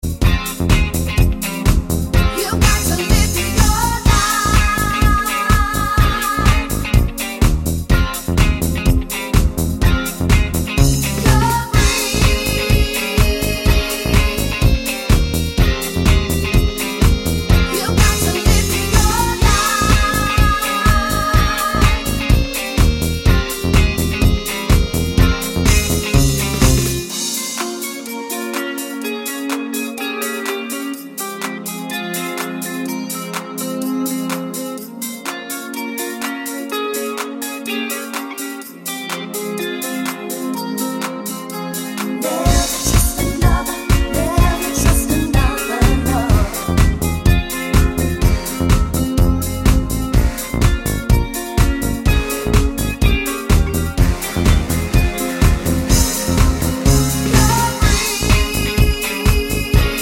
no Backing Vocals Dance 3:59 Buy £1.50